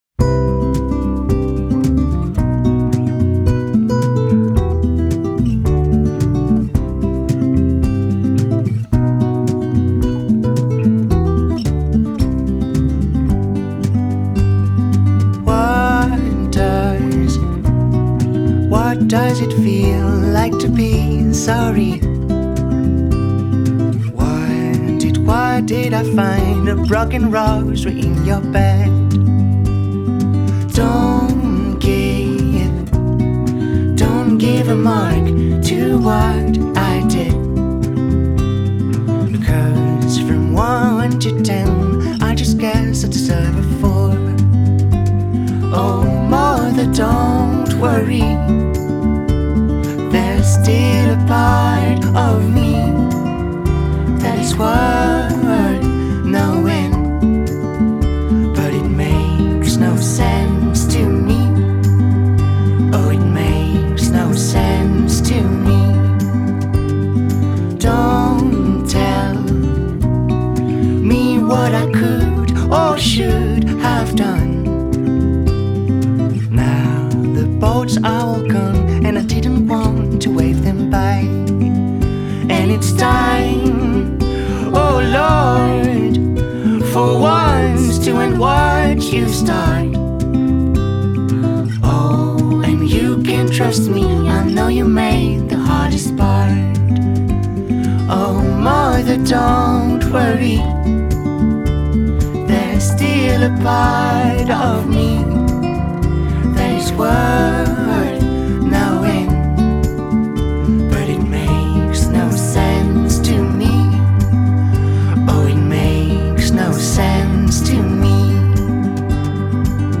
French pop folk duo
Great moving sound.